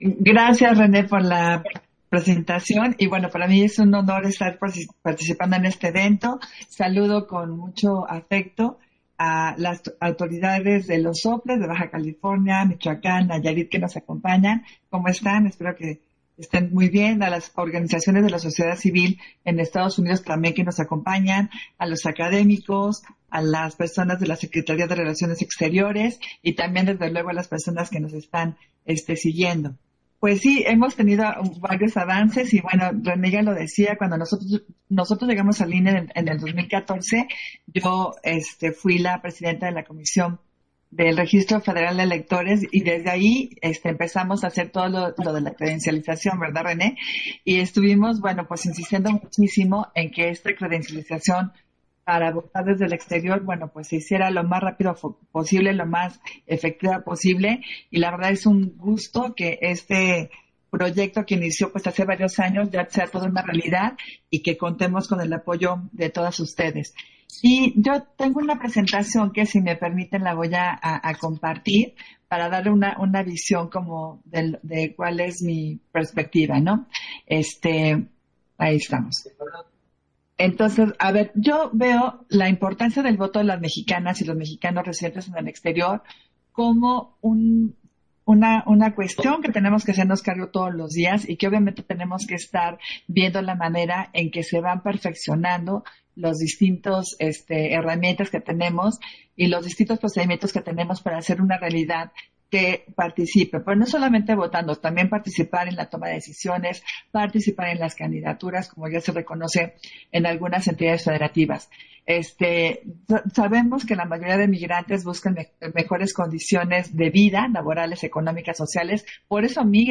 Intervención de Adriana Favela, en el segundo conversatorio, Reflexiones en torno a la importancia de la participación política de las y los mexicanos en el extranjero